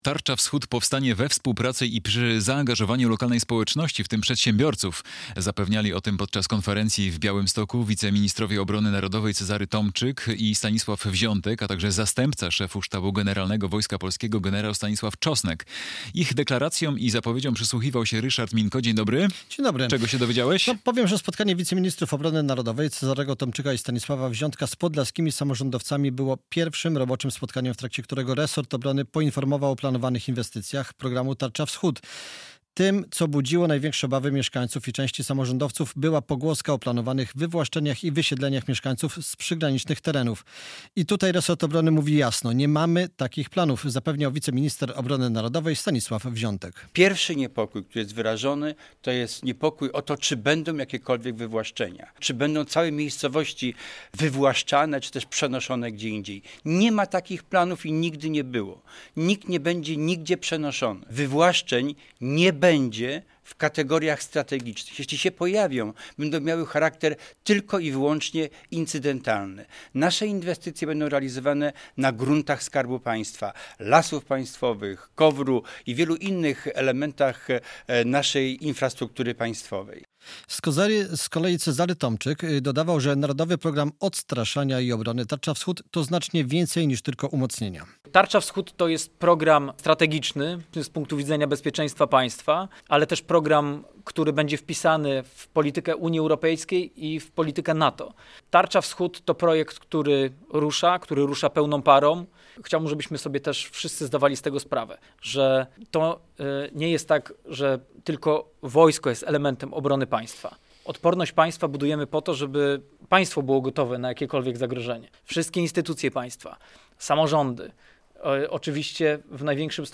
Tarcza Wschód ma powstać we współpracy i przy zaangażowaniu lokalnej społeczności - relacja